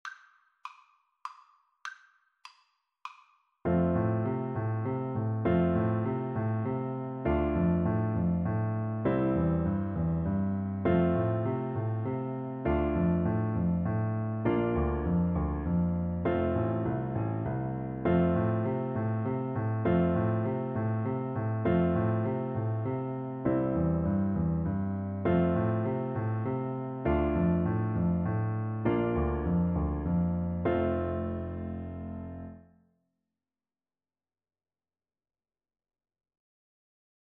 is a Korean folk song
3/4 (View more 3/4 Music)